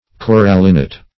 Corallinite \Cor"al*lin*ite\, n.